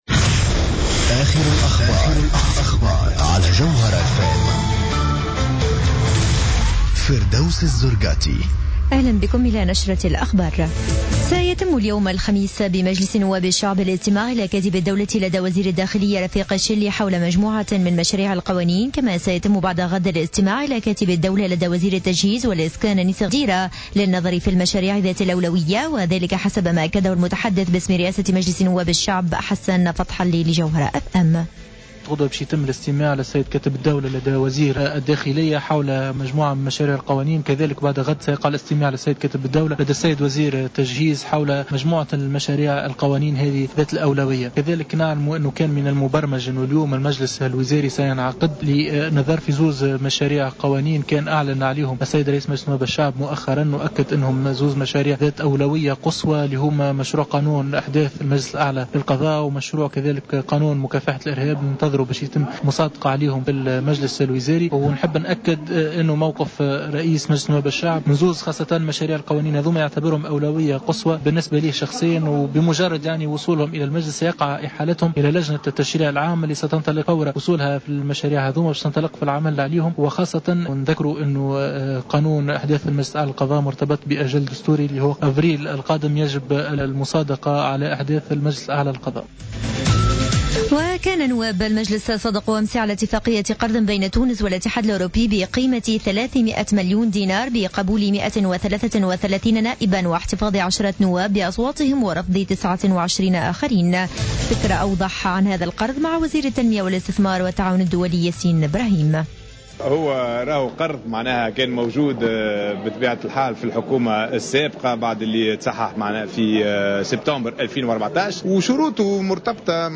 نشرة أخبار منتصف الليل ليوم الخميس 05 مارس 2015